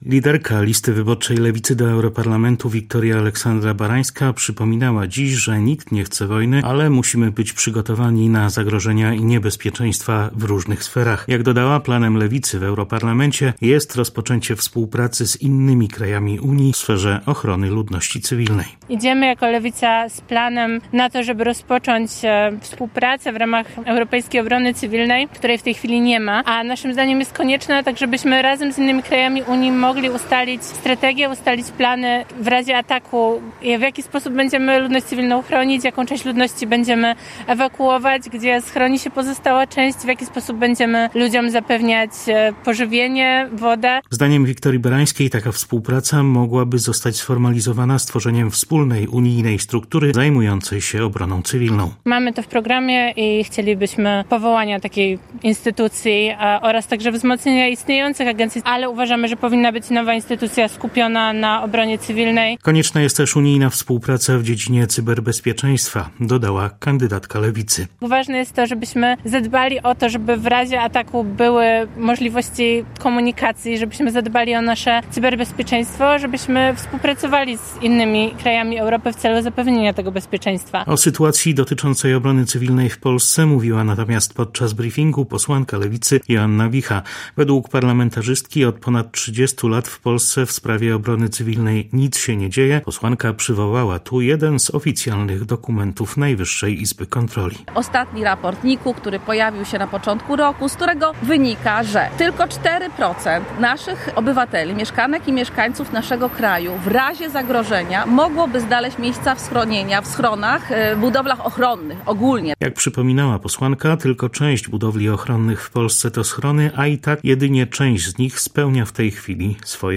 Bezpieczeństwo ludności cywilnej w przypadku zagrożeń i obrona cywilna - to tematy konferencji prasowej liderki podkarpackiej listy Lewicy do Europarlamentu